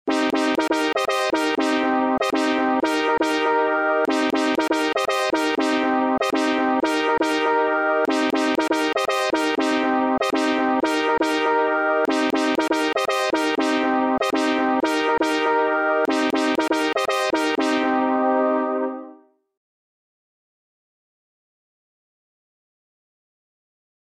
the-meow-meow-.mp3